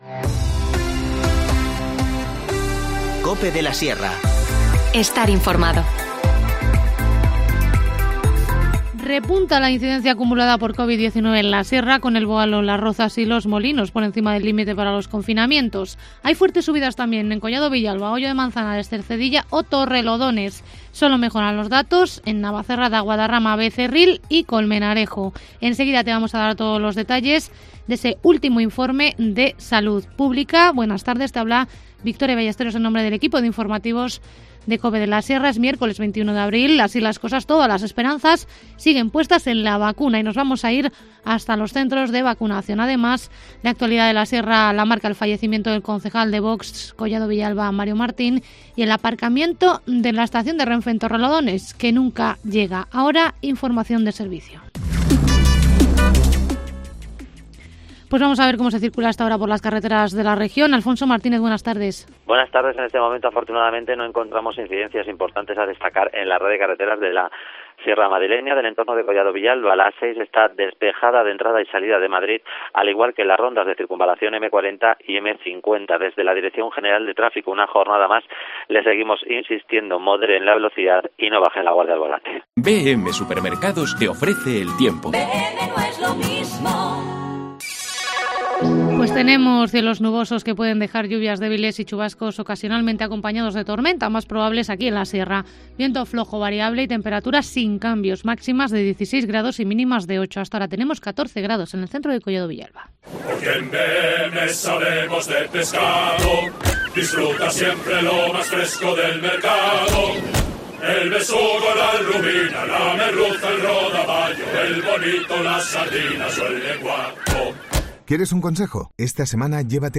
Informativo Mediodía 21 abril